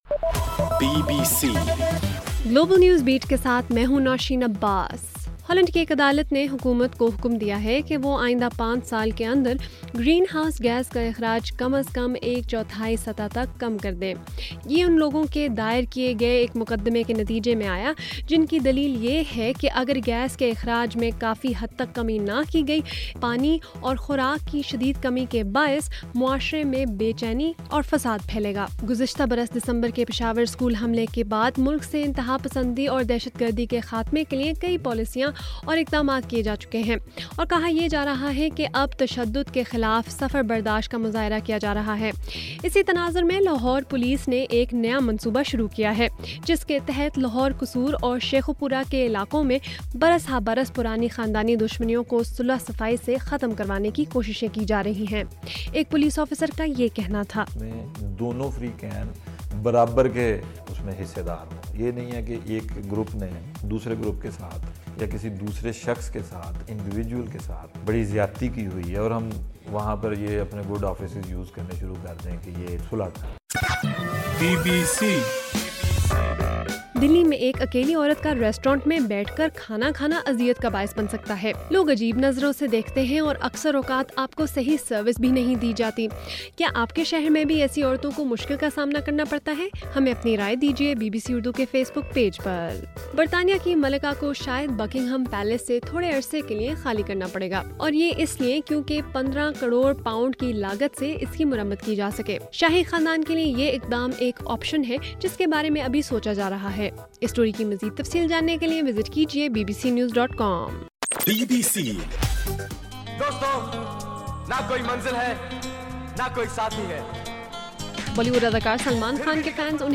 جون 24: رات 8 بجے کا گلوبل نیوز بیٹ بُلیٹن